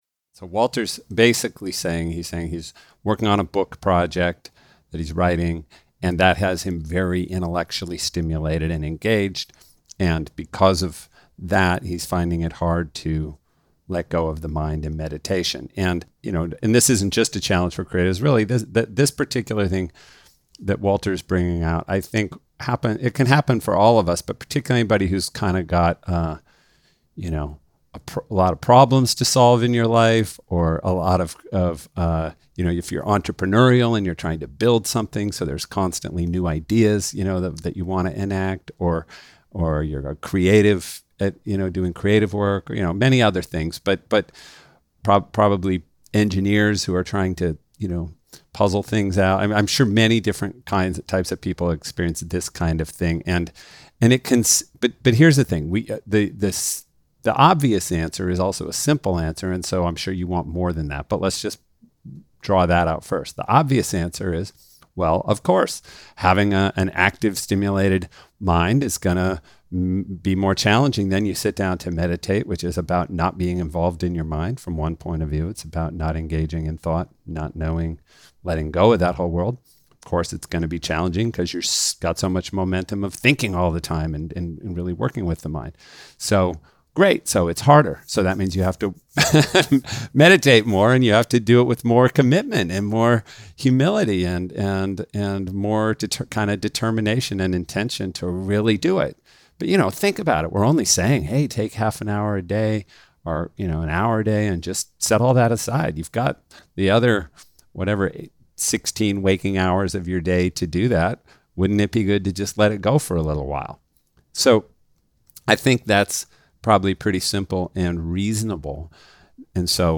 Q&A Meditation Listen